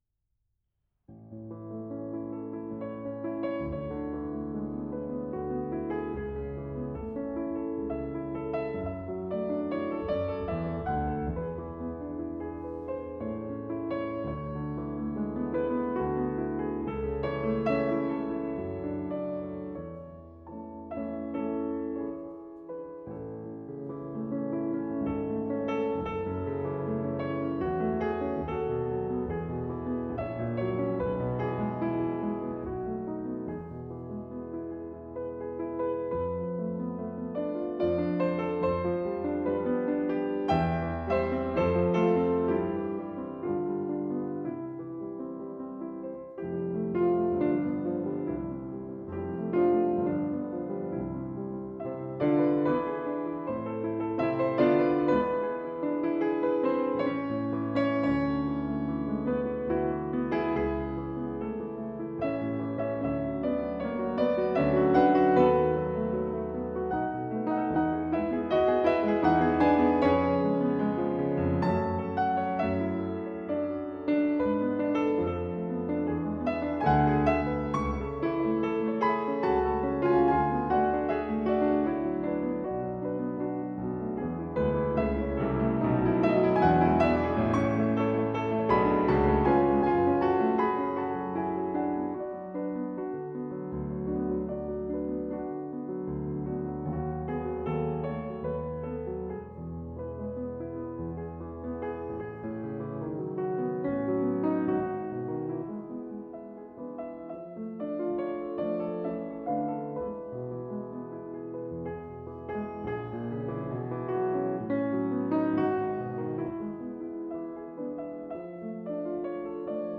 Klavier - Duo